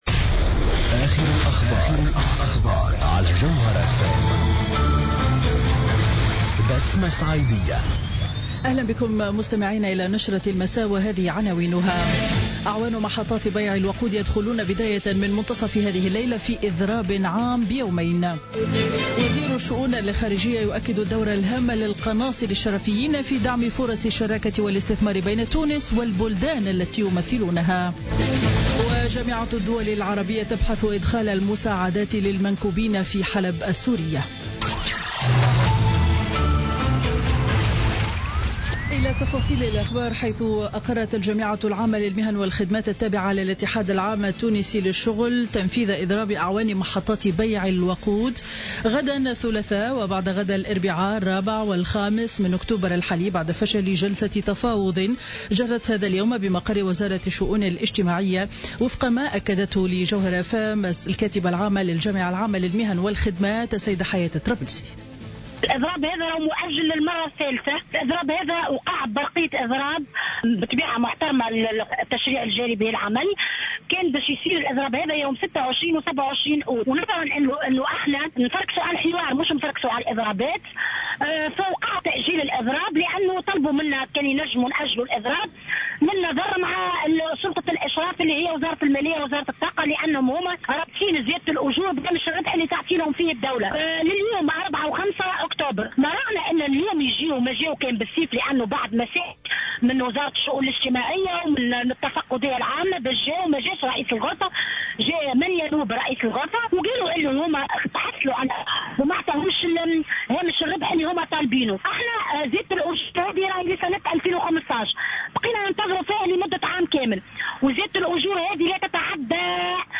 نشرة أخبار السابعة مساء ليوم الاثنين 3 أكتوبر 2016